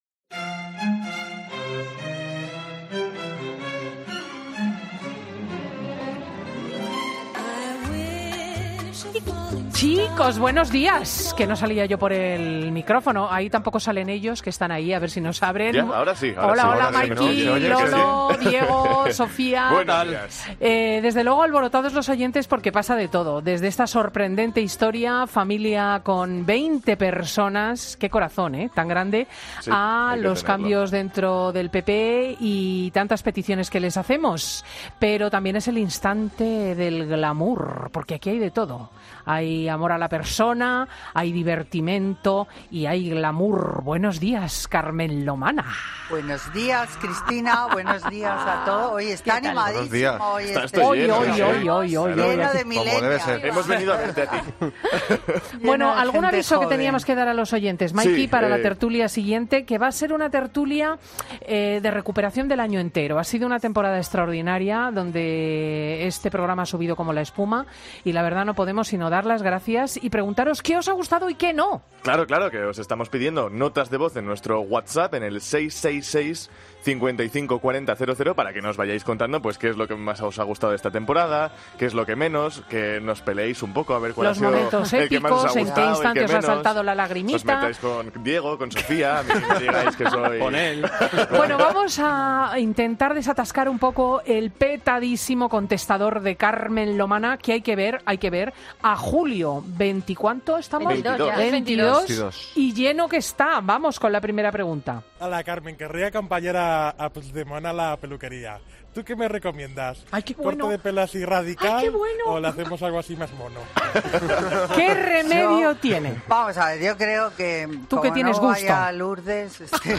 Escucha ahora el Consultorio de Carmen Lomana, en FIN DE SEMANA . Presentado por Cristina López Schlichting, prestigiosa comunicadora de radio y articulista en prensa, es un magazine que se emite en COPE, los sábados y domingos, de 10.00 a 14.00 horas, y que siguen 769.000 oyentes, según el último Estudio General de Medios conocido en noviembre de 2017 y que registró un fuerte incremento del 52% en la audiencia de este programa.